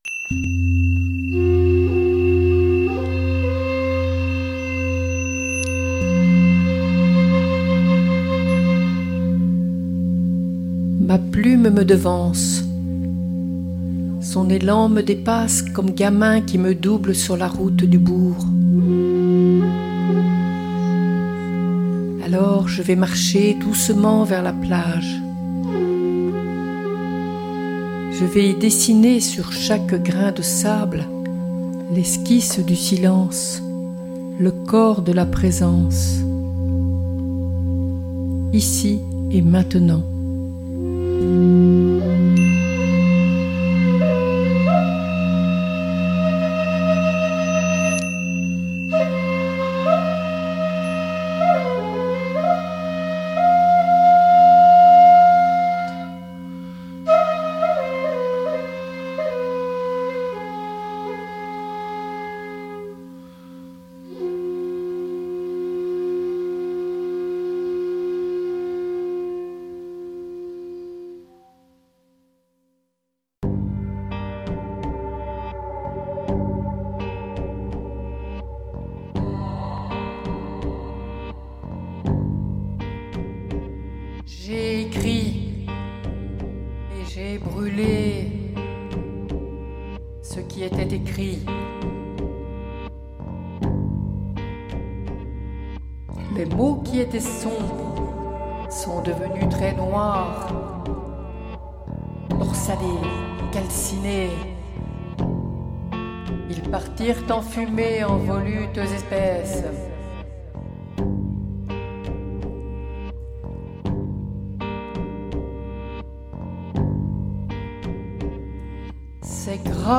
VOIX DE PLUME Poèmes mis en musique
bols tibétains, sax et clarinette.